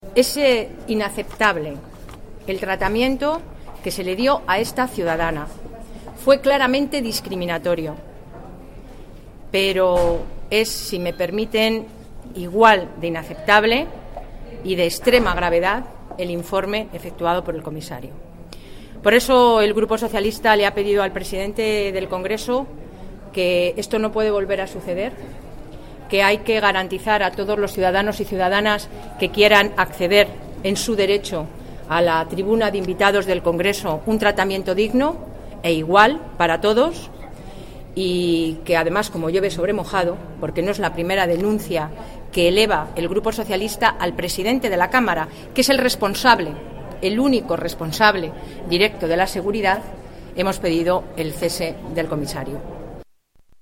Declaraciones de Soraya Rodríguez en el Congreso el 29/10/2013